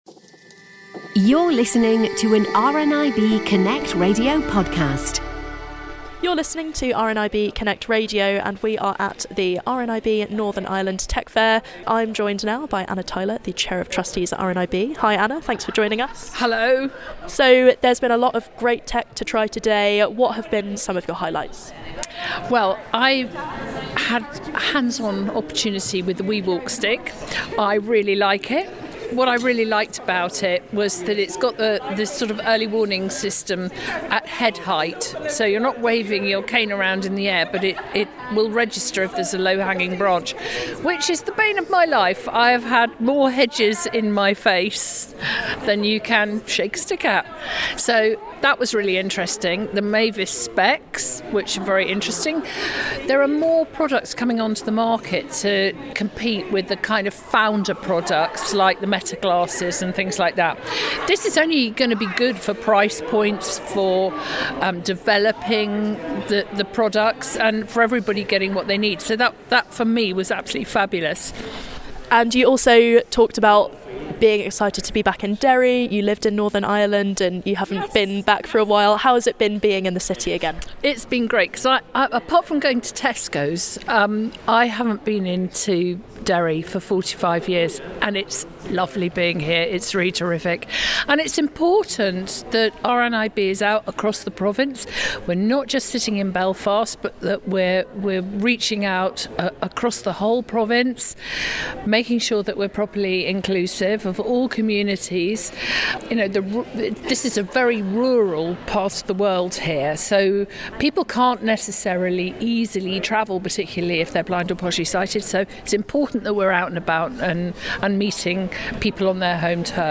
RNIB Northern Ireland Technology Fair 2026 took place on Wednesday 11th of February at the Foyle Arena in Derry/Londonderry.